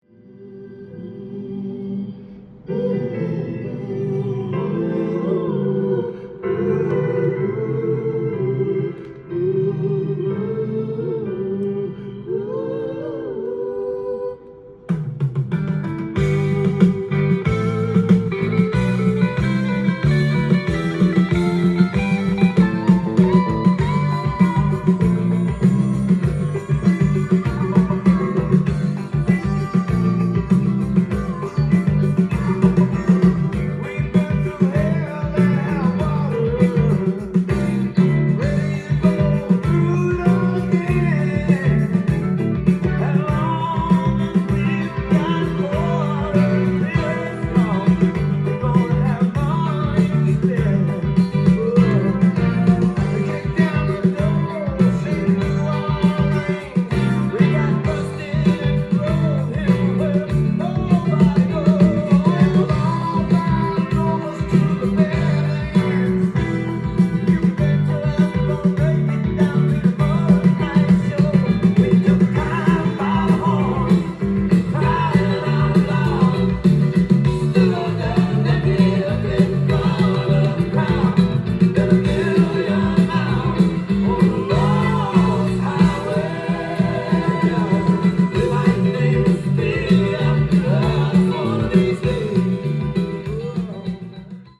ジャンル：ROCK & POPS
店頭で録音した音源の為、多少の外部音や音質の悪さはございますが、サンプルとしてご視聴ください。
ツイン・ギターの絡みも冴え渡り、力強いドライブ感を楽しめる一枚です！